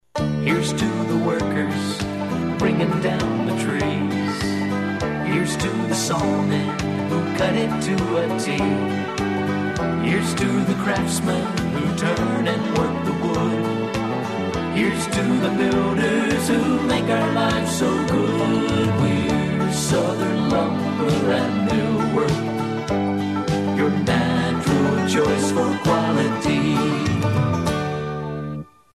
You can still hear the jingle on the radio today.